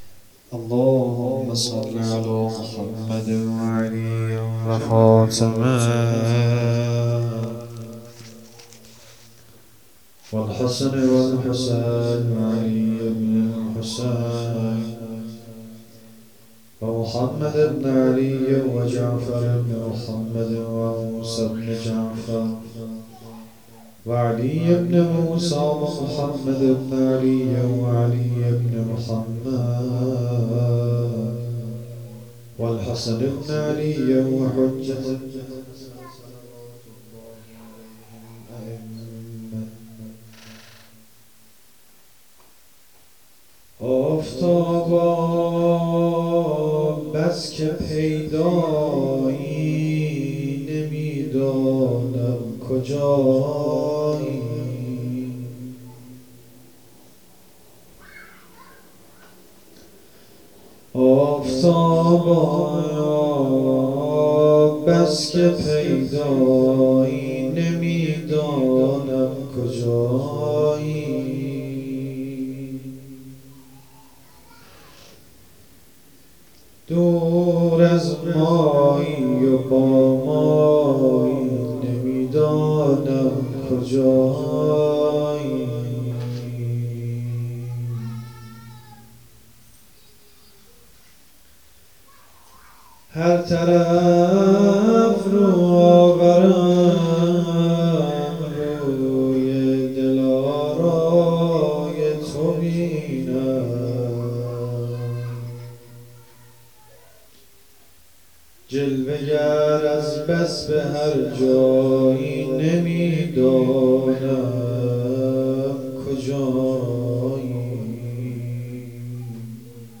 شب سوم(شام غریبان) فاطمیه 1397